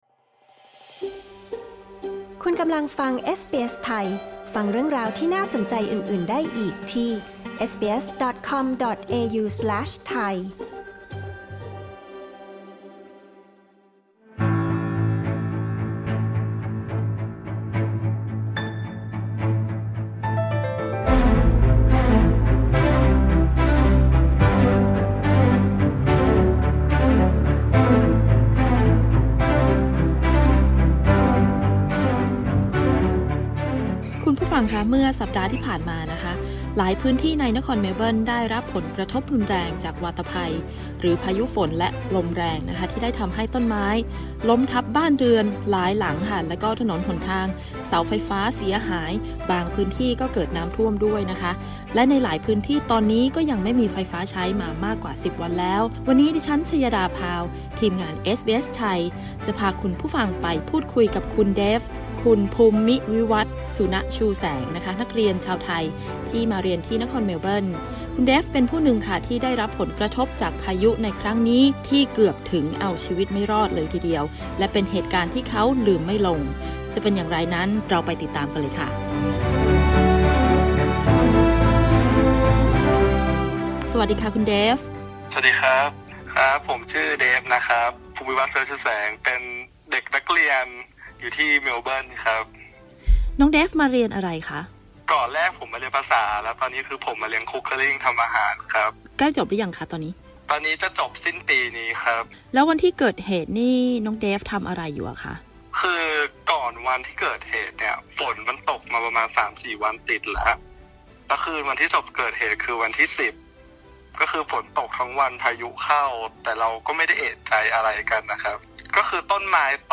ประเด็นสำคัญในการสัมภาษณ์ เกิดเหตุการณ์อะไรในระหว่างเกิดพายุที่นครเมลเบิร์น ความเสียหายเนื่องจากพายุ ความช่วยเหลือที่ได้รับ วิธีการฟื้นฟูร่างกายและจิตใจ